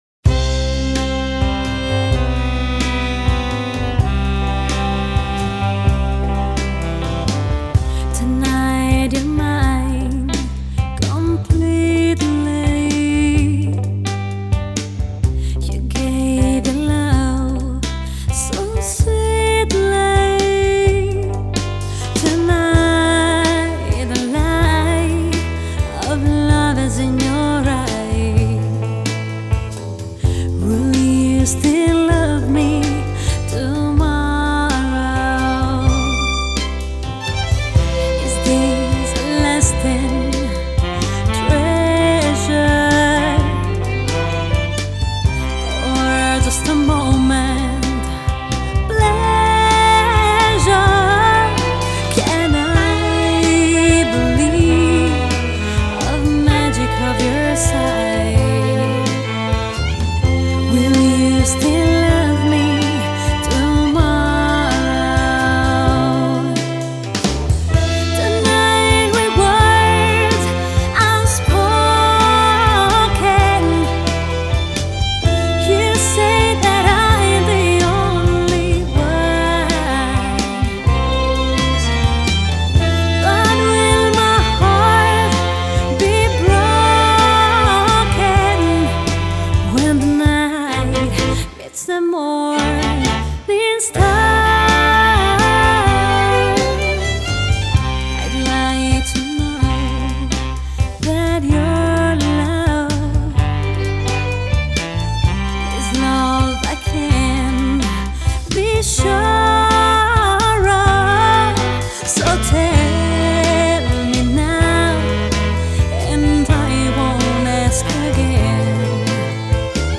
Revival ‘50 ‘60 Italia America